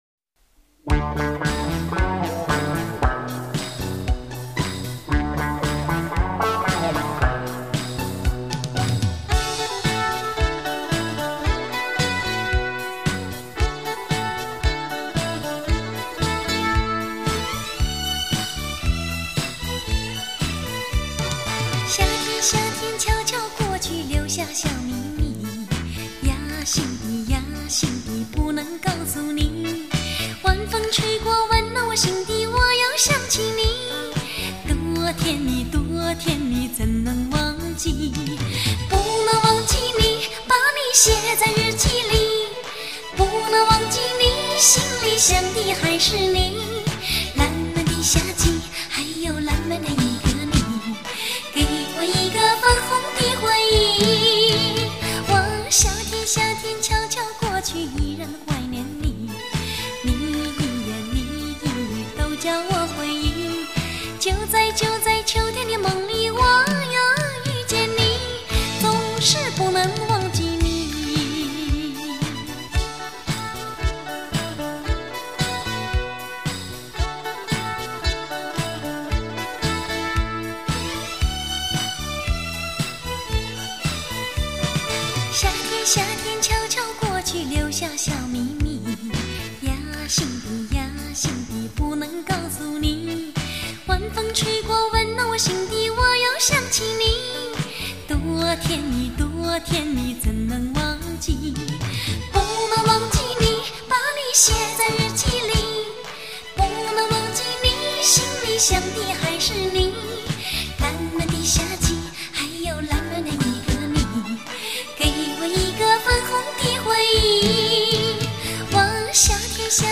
主要通过节奏轻快的情